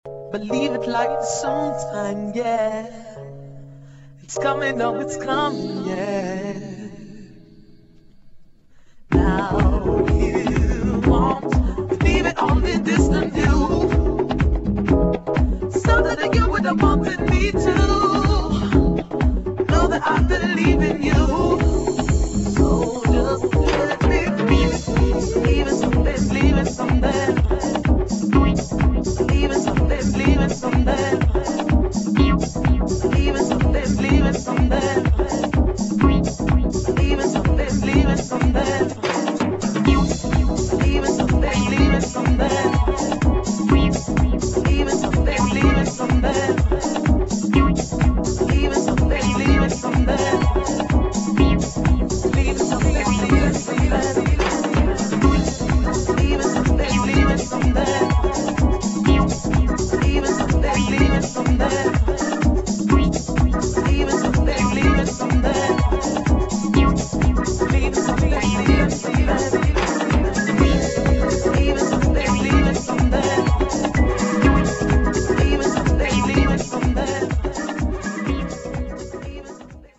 [ HOUSE / JAZZ HOUSE ]